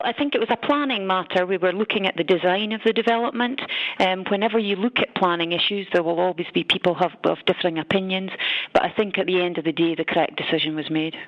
Council Leader Jenny Laing, explains the concerns some had: